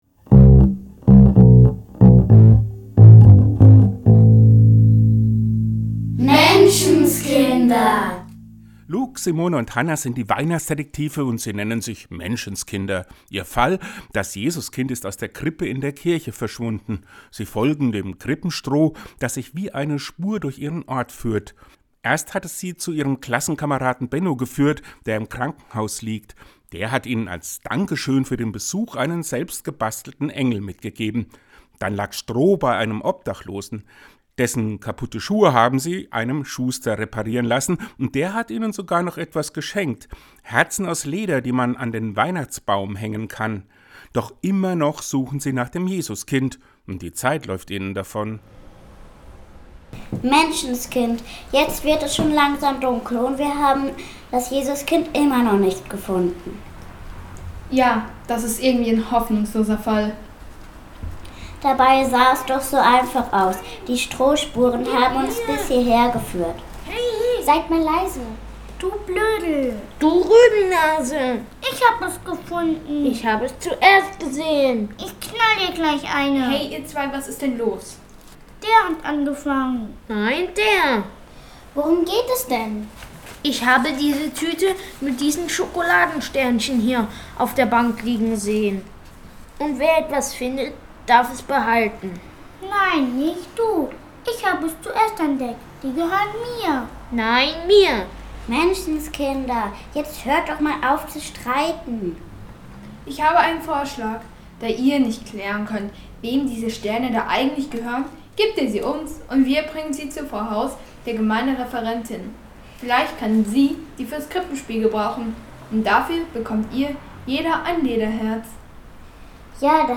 Die Weihnachtsdetektive Teil 4 Hörspiel im Advent Bei uns im Kirchenmagazin sind in diesem Advent drei Weihnachtsdetektive unterwegs.